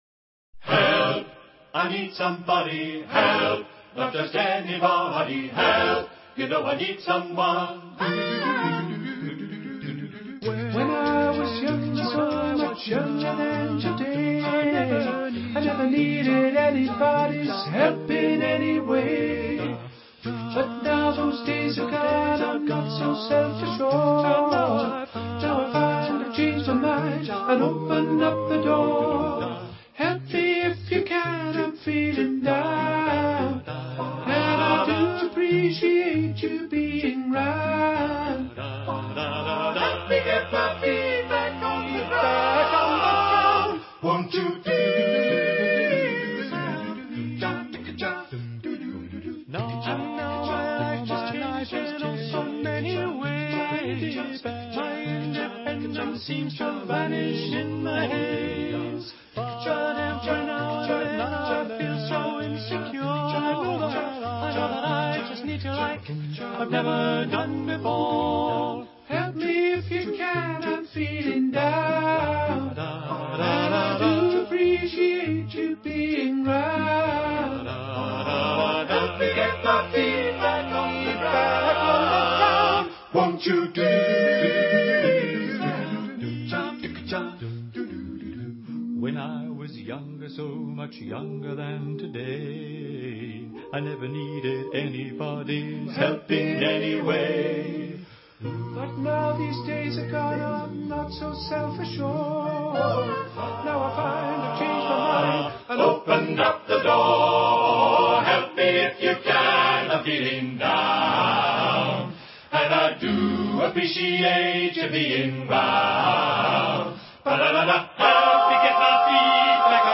[ Rock - 02:30 / 3.4Mb ] [Info] [